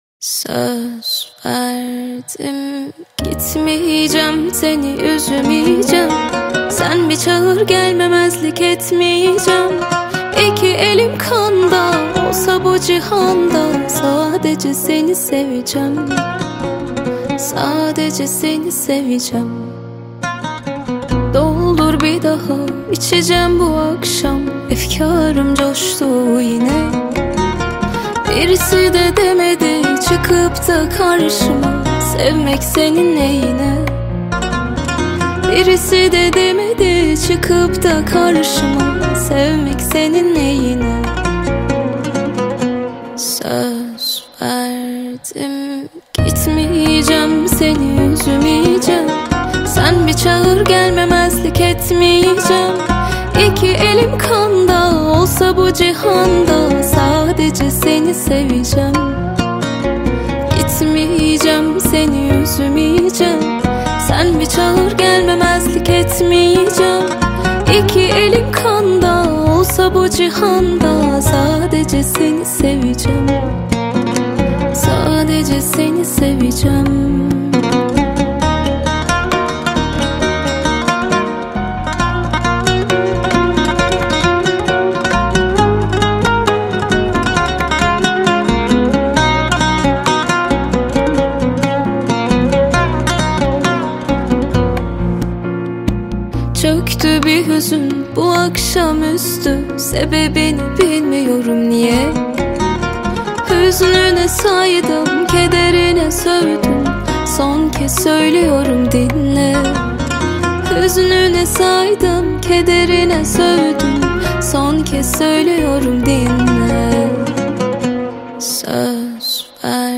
Жанр: Турецкие песни